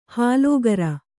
♪ hālōgara